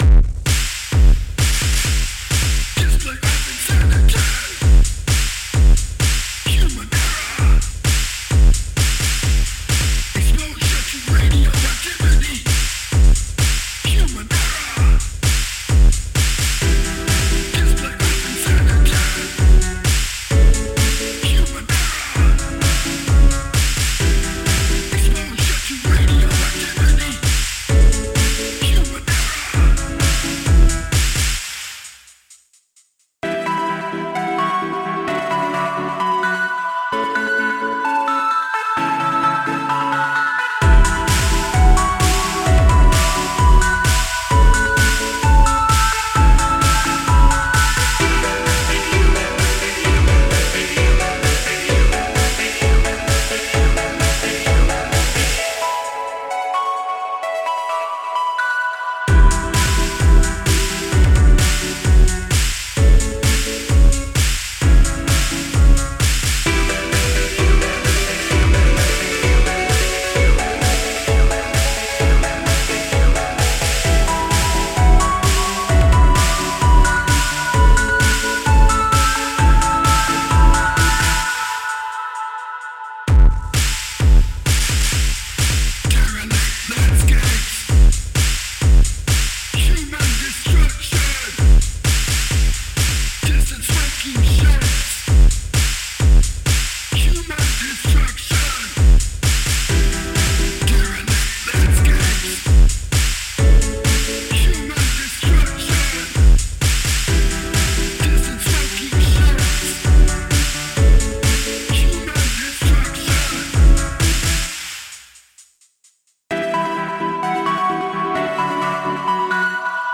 music vocals